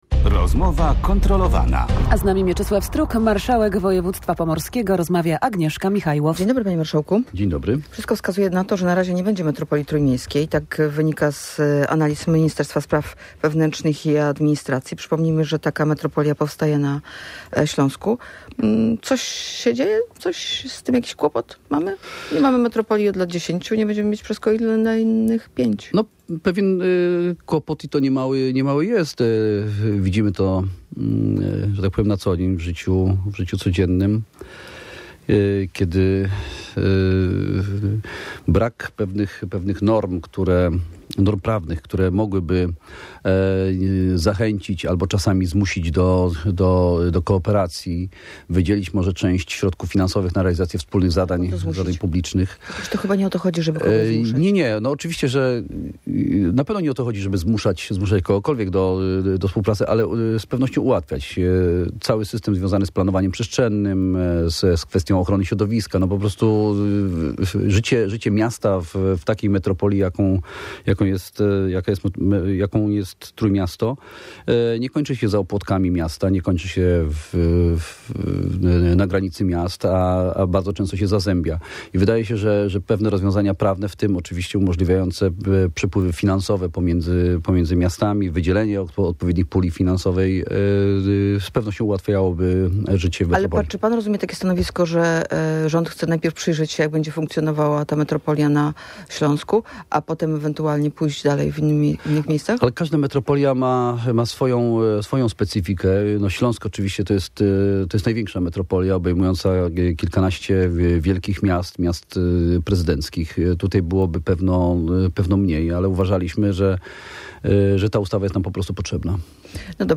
Mieczysław Struk w Radiu Gdańsk tłumaczył, dlaczego projekt wspólnego biletu komunikacji miejskiej jest tak ważny.